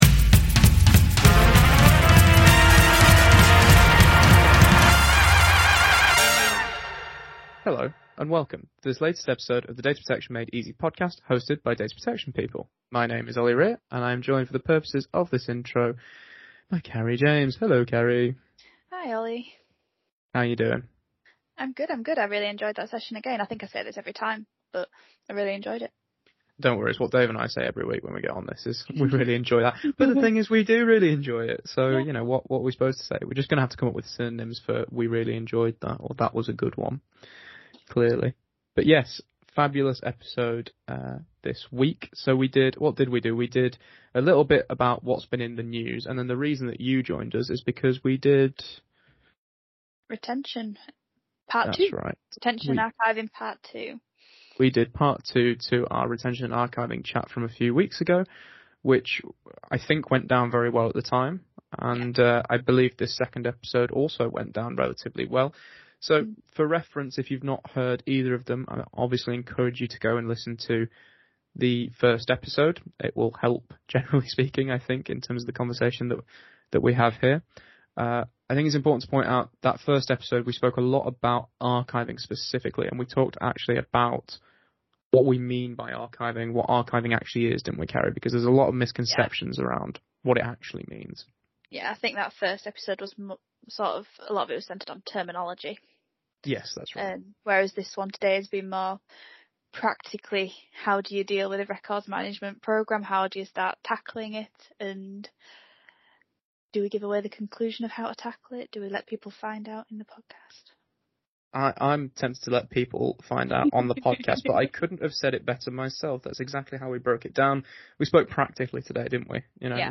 In part 2 of our Retention and Archiving conversation